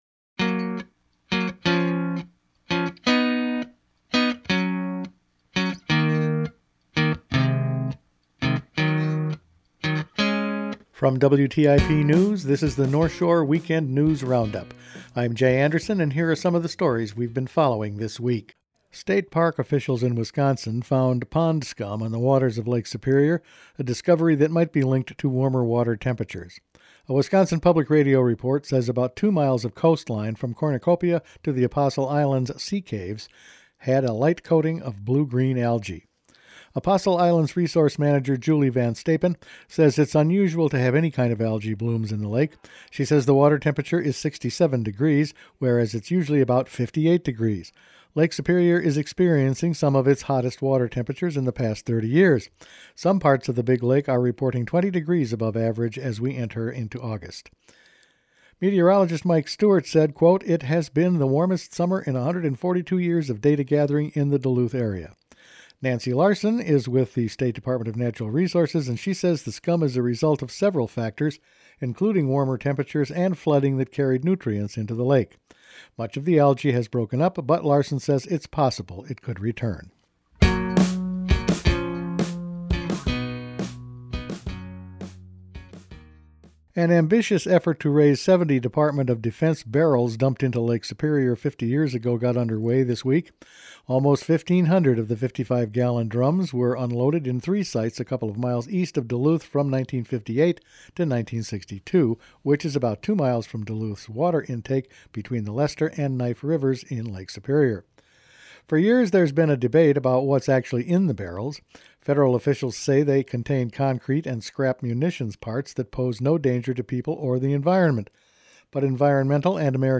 Each weekend WTIP news produces a round up of the news stories they’ve been following this week. BWCAW land swap, disaster loan aid, a slight kerfuffle at the Duluth federal building, Lake County has a new school superintendent and raising mystery barrels in the big lake, where there apparently was pond scum for a while…all in this week’s news.